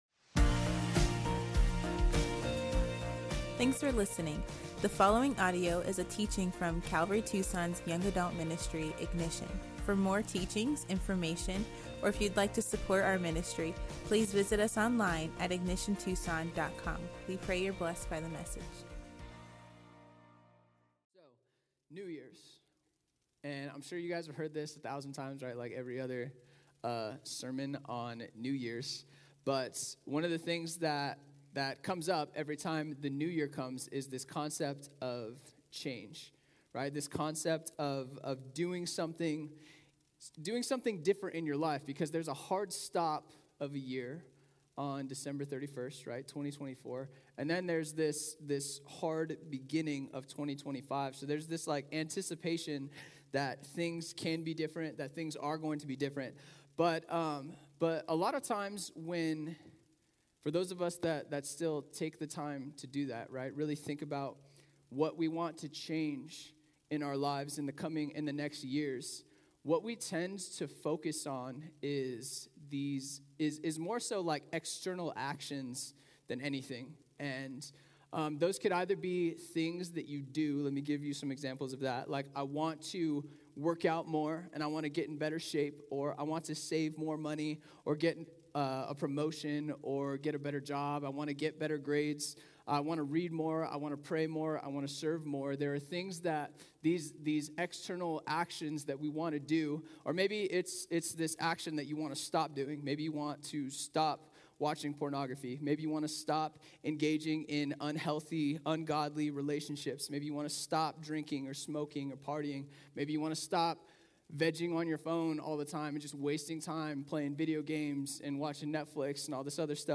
This teaching emphasizes the importance of focusing on the condition of our hearts, not just outward actions. It highlights how we're most vulnerable to temptation when we're tired or distracted and reminds us that salvation comes through God's grace, not our works.